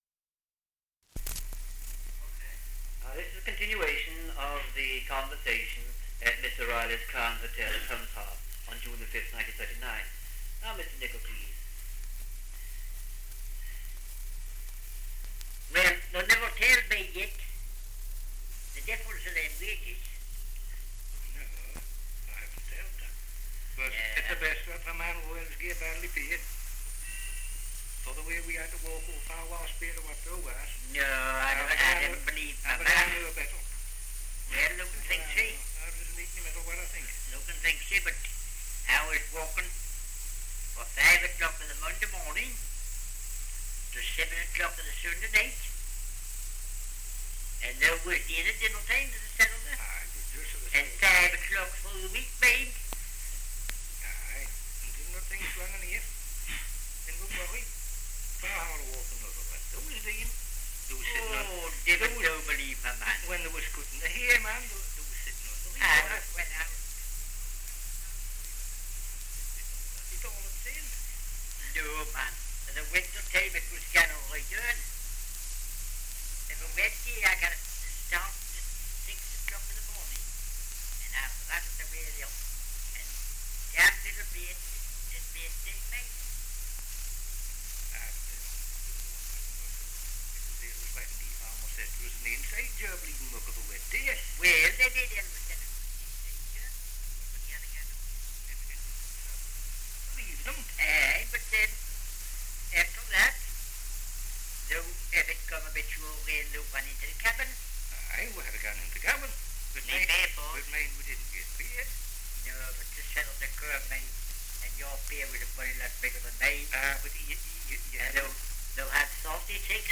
Dialect recording in Humshaugh, Northumberland
78 r.p.m., cellulose nitrate on aluminium